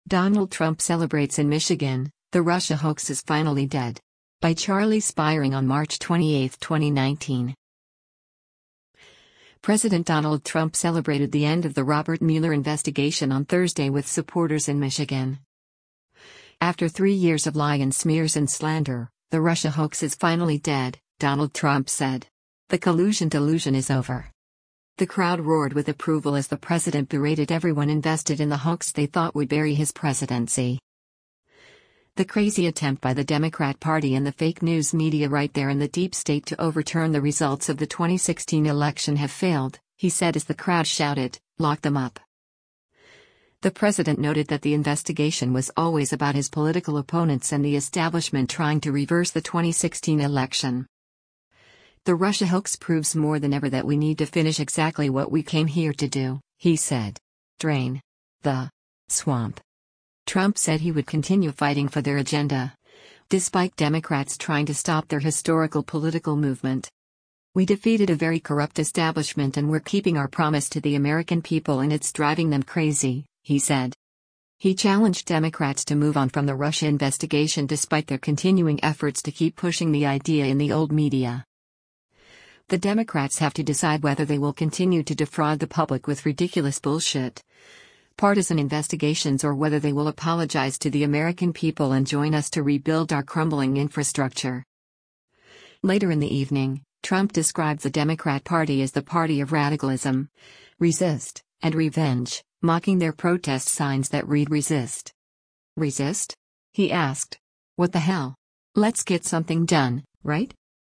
US President Donald Trump arrives for a campaign rally in Grand Rapids, Michigan on March
The crowd roared with approval as the president berated everyone invested in the “hoax” they thought would bury his presidency.
“The crazy attempt by the Democrat party and the fake news media right there and the deep state to overturn the results of the 2016 election have failed,” he said as the crowd shouted, “Lock Them UP!”